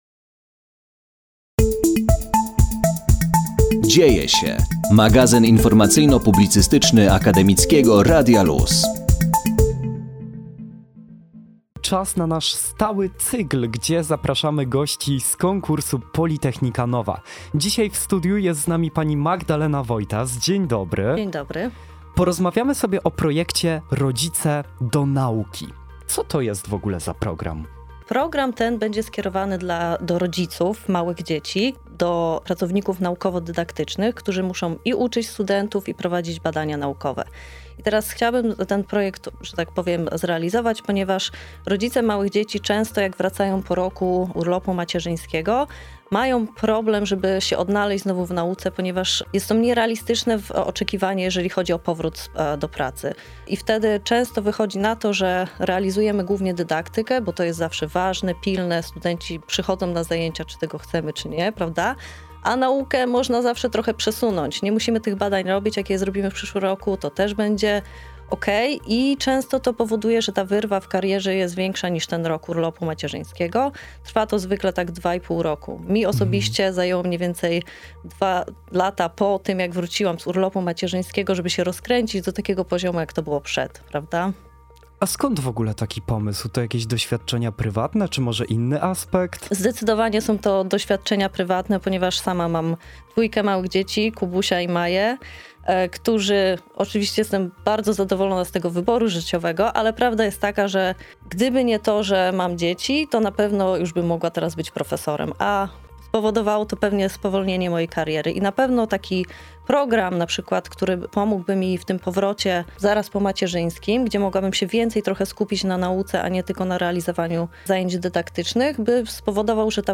POLYTECHNICA NOVA – wywiady z laureatami pierwszego etapu konkursu
Wraz z nim ruszył nasz cykl rozmów z przedstawicielami zakwalifikowanych wniosków na antenie Akademickiego Radia Luz.